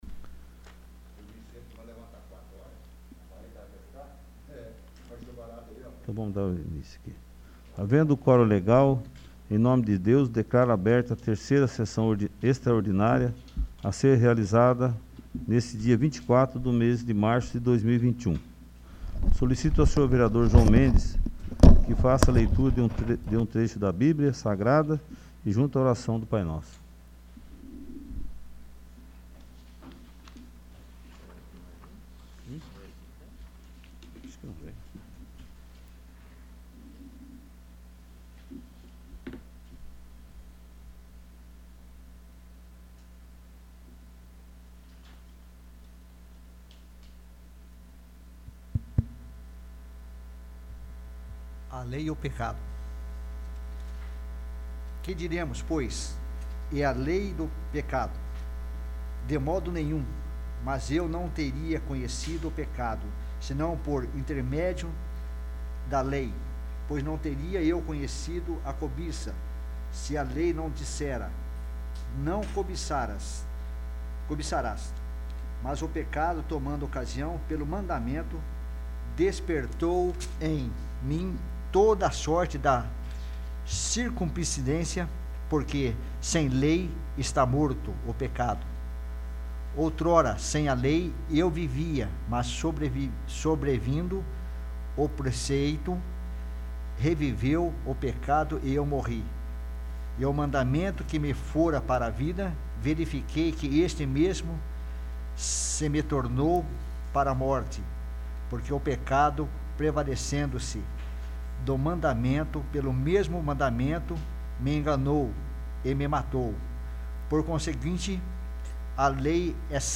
3º. Sessão Extraordinária